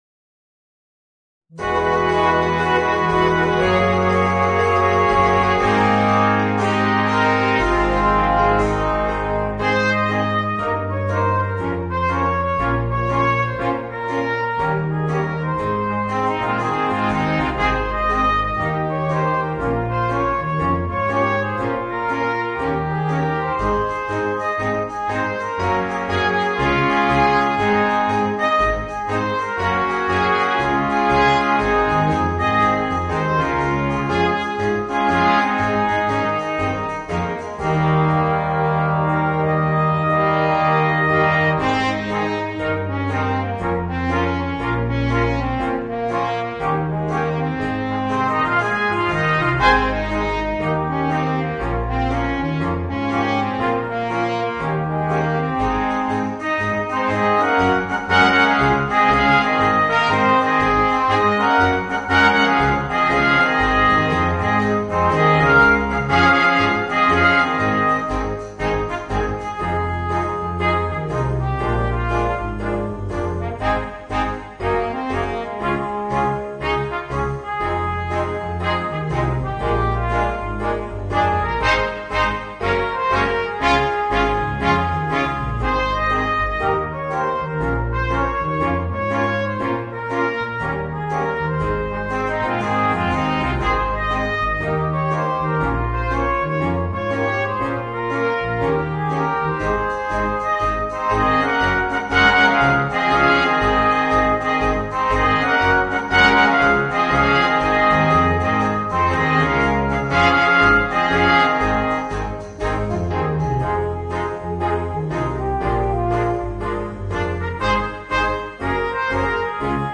Voicing: 2 Trumpets, Horn, Trombone, Tuba and Drums